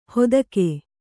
♪ hodake